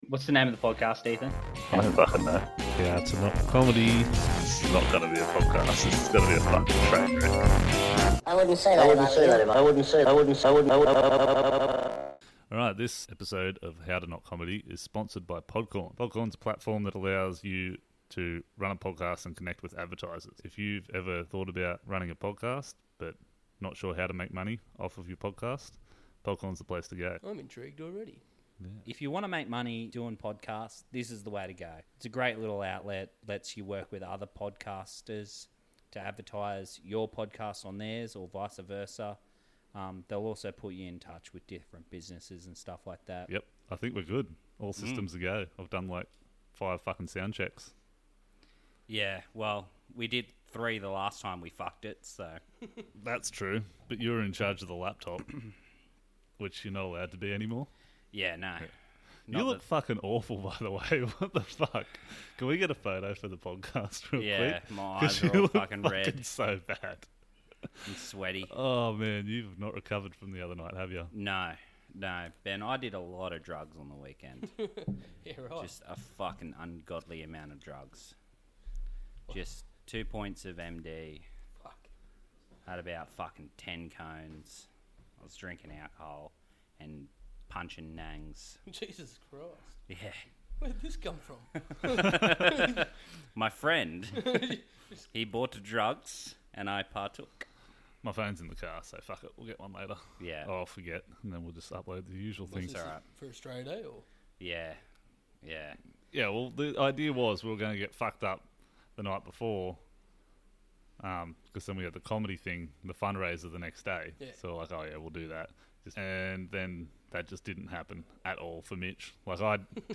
At least we didn't fuck the recording on this time.